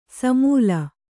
♪ samūla